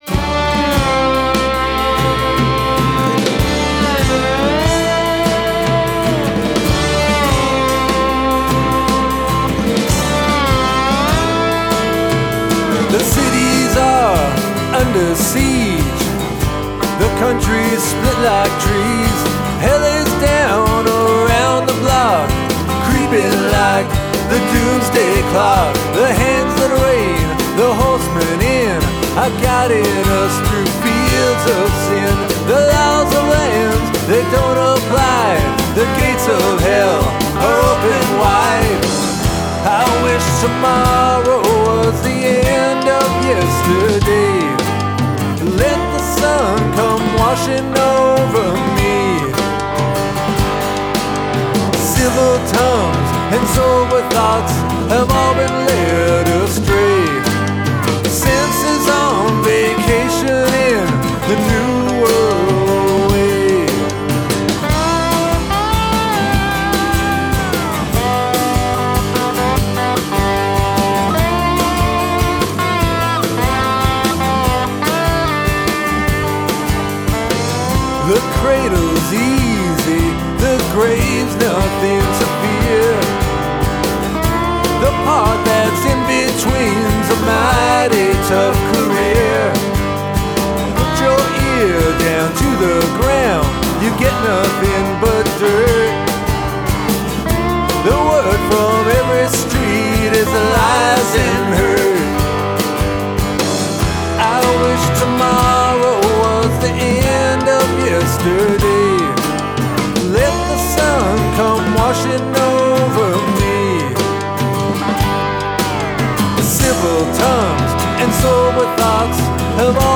guitars and vocals